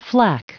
Prononciation du mot flak en anglais (fichier audio)
Prononciation du mot : flak